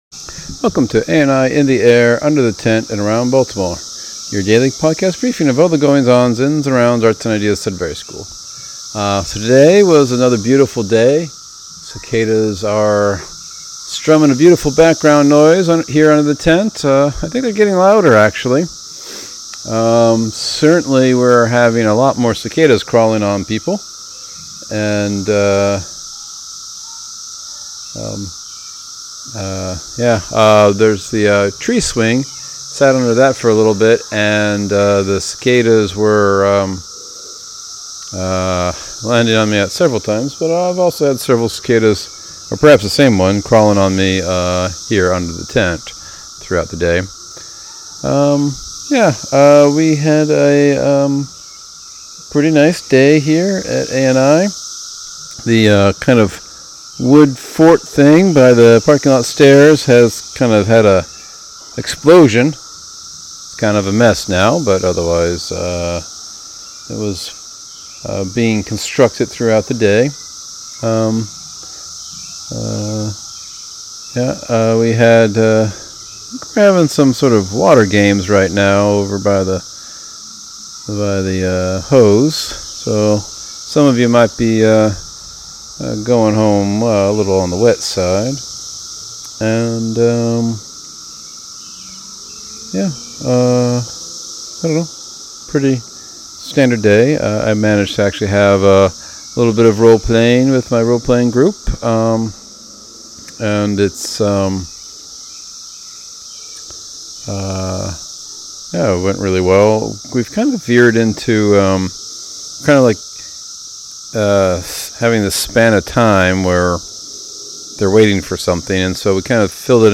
Beautiful day, cicadas strumming loudly, landing on people.